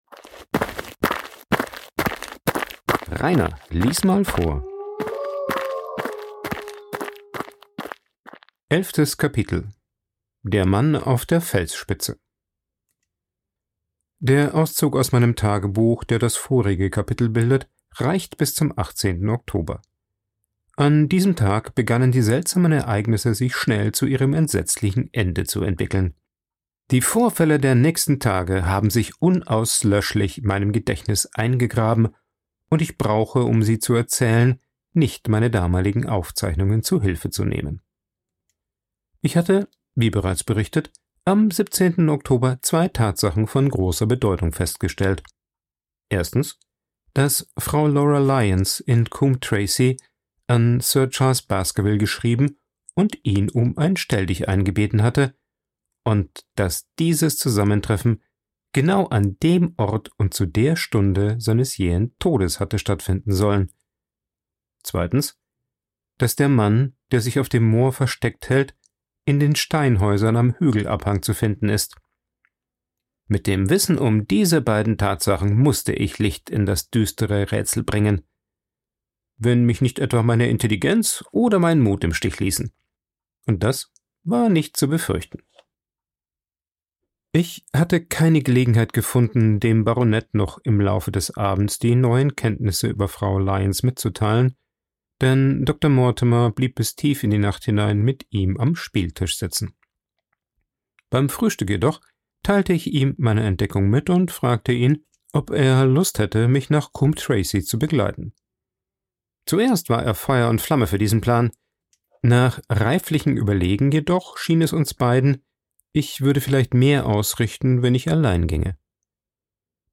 aufgenommen und bearbeitet im Coworking Space Rayaworx, Santanyí, Mallorca.